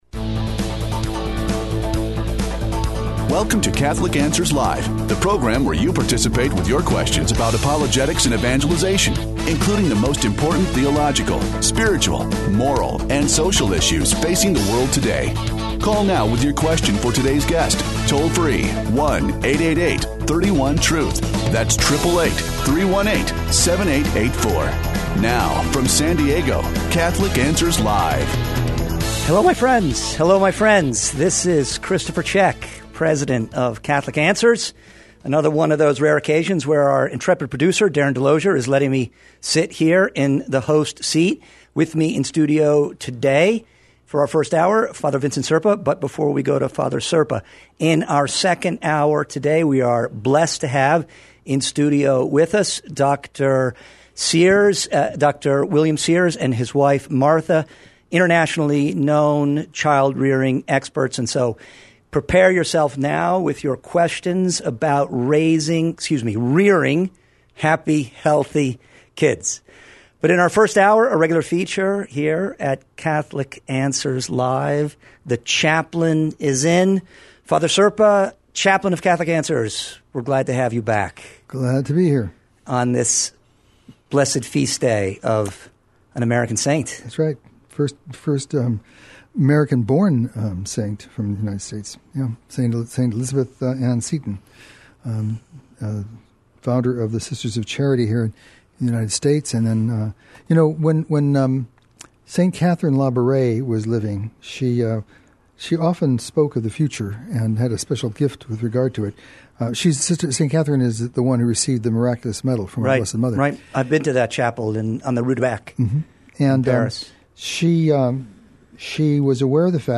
takes questions of a pastoral nature in this hour devoted to the care of souls, growth in the spiritual life, and healthy relationships.